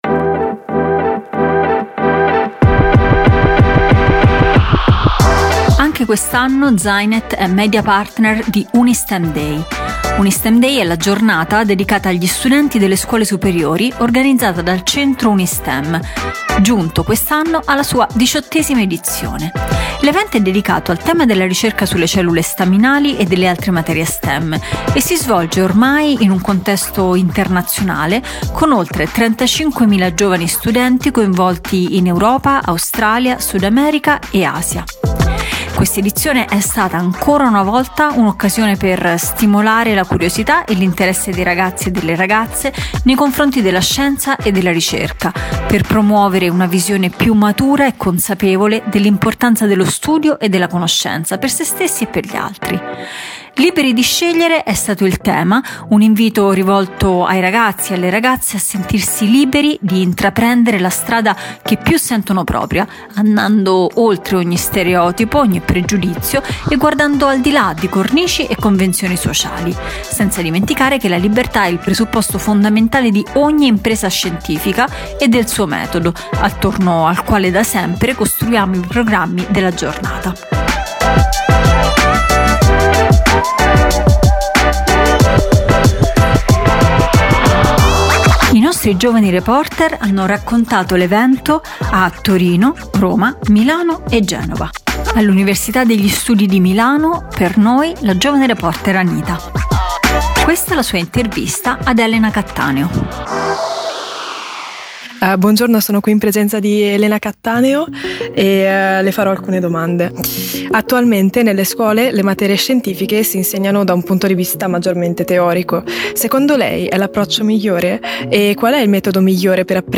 Il racconto della giornata attraverso le interviste dei giovani reporter da tutta Italia